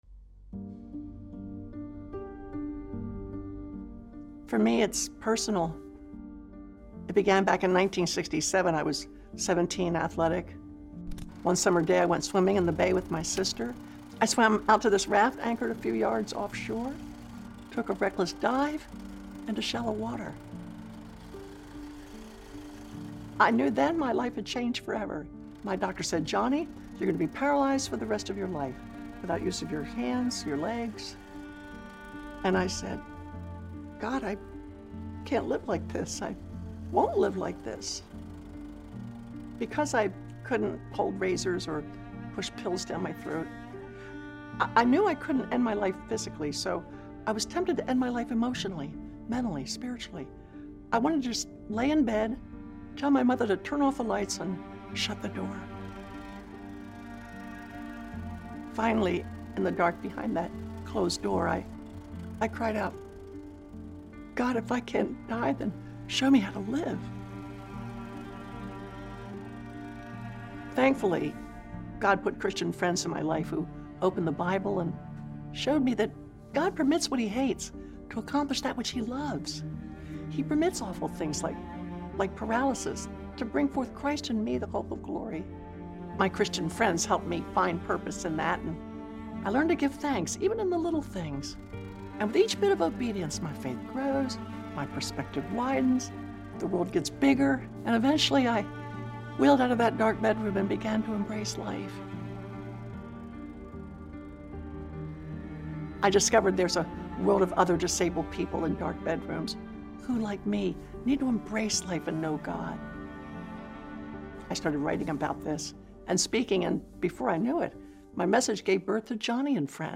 TW22 Plenary- Tada.MP3